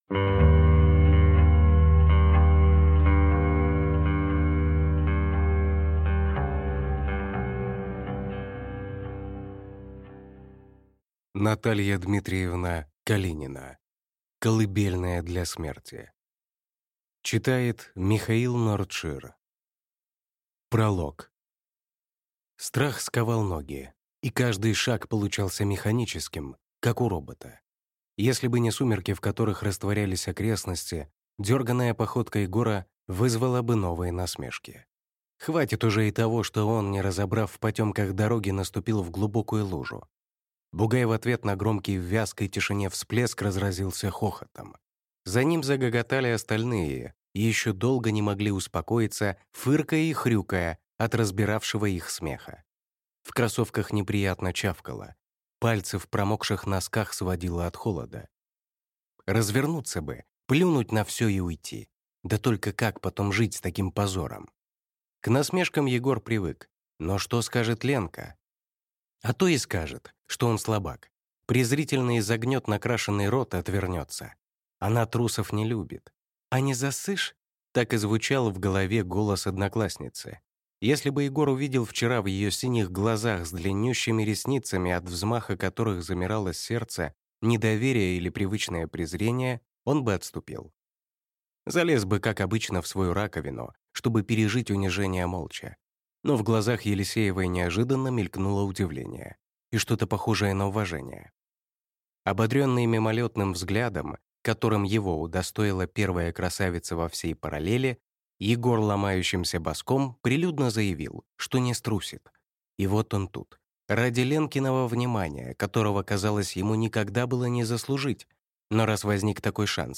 Аудиокнига Колыбельная для смерти | Библиотека аудиокниг